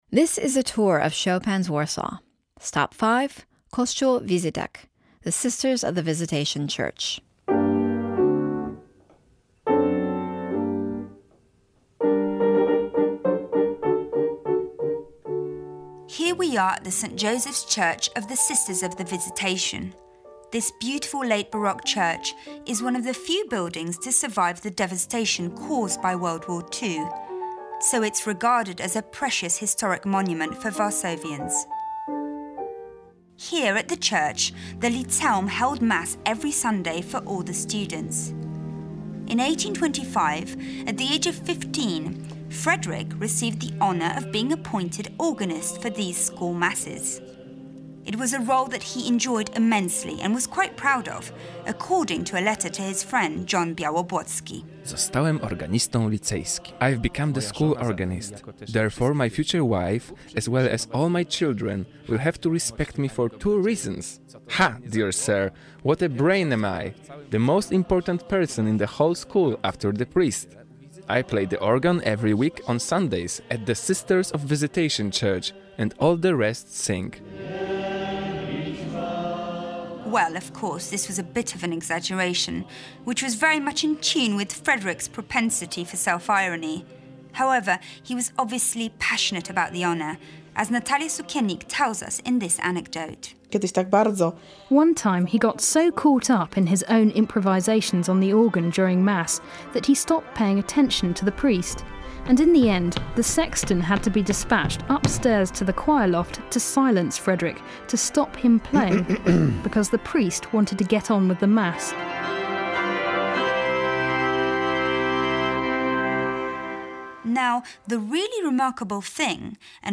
The result is a kind of historical documentary with direction, a walk guided by good stories and aural atmospheres.